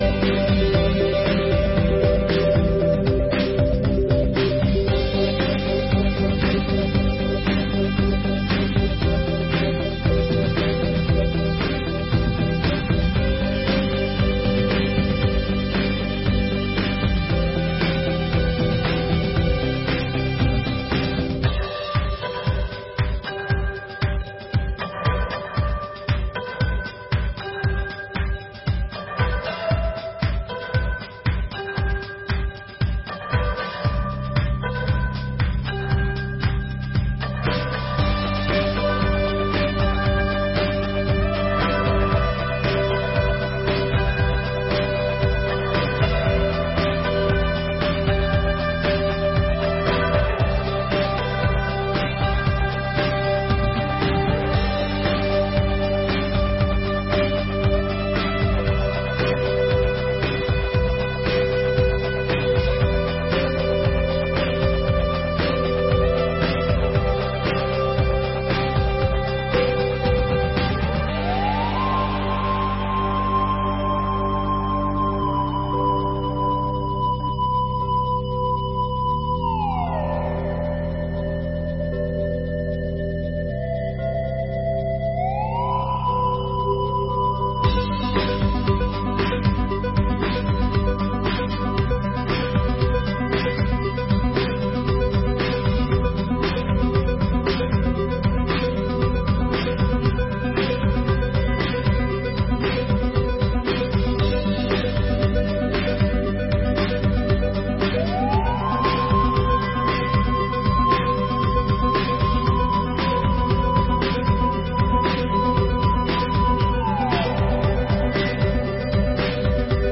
Audiências Públicas de 2022